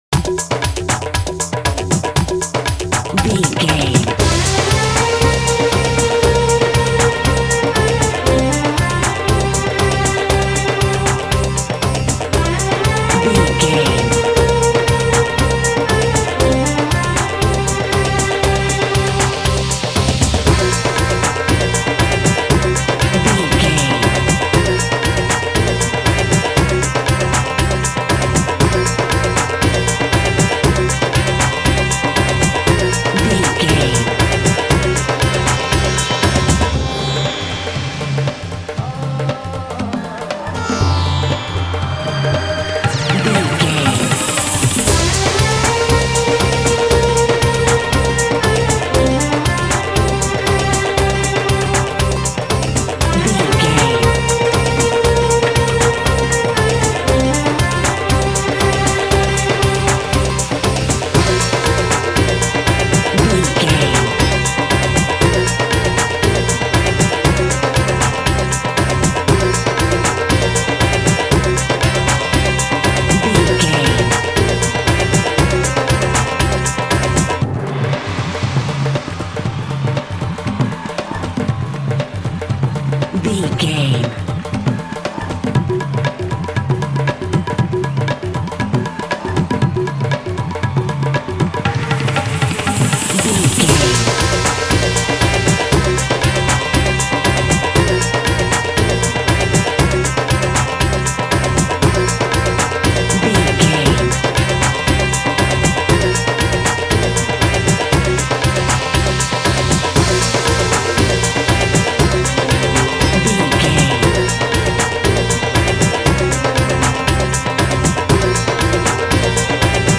Ionian/Major
tabla
lively
energetic
uplifting